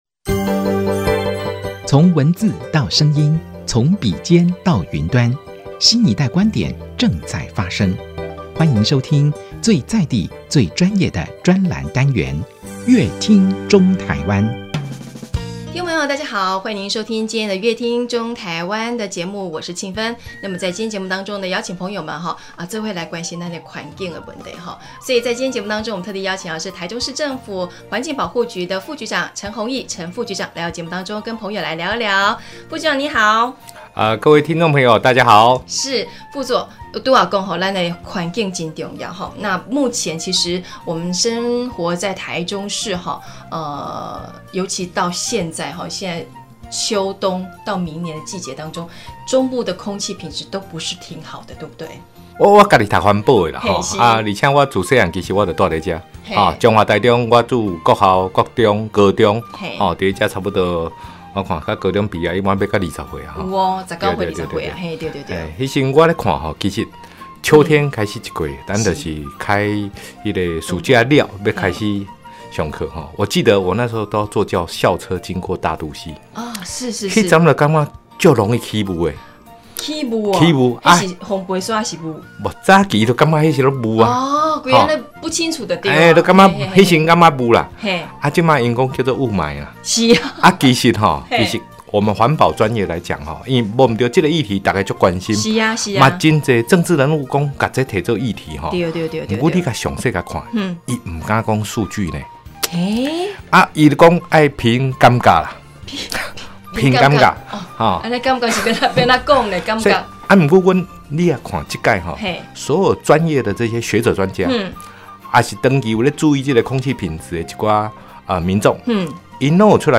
本集來賓：台中市政府環境保護局陳宏益副局長 本集主題：「傾聽民意 打造4A等級的優等環境」